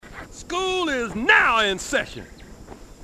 Halo Dialogue Snippets